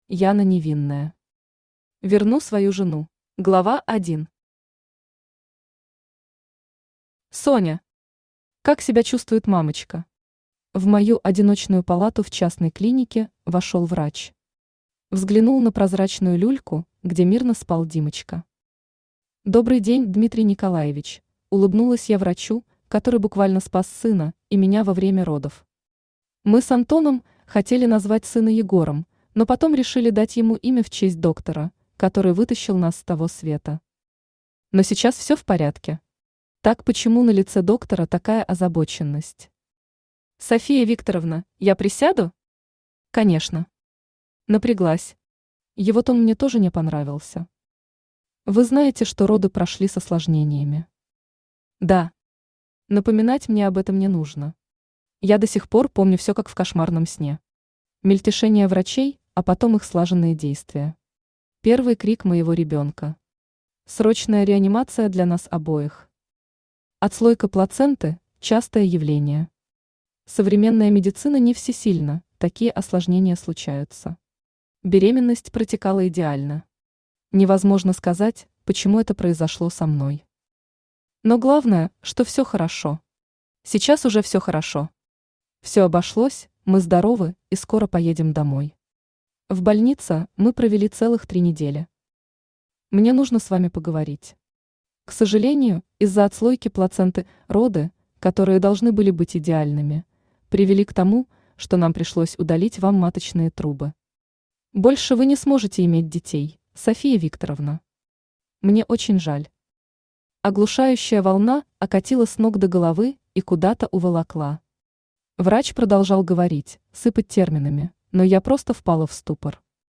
Аудиокнига Верну свою жену | Библиотека аудиокниг
Aудиокнига Верну свою жену Автор Яна Невинная Читает аудиокнигу Авточтец ЛитРес.